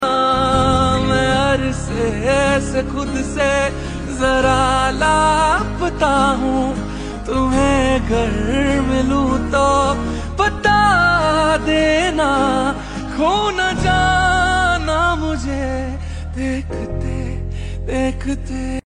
Strings, Beats, and Everything Nice
Well, look no further – it’s all in the guitars, my friend.